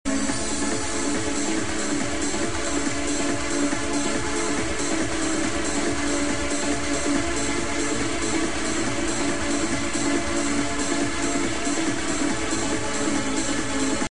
Powered by: Trance Music & vBulletin Forums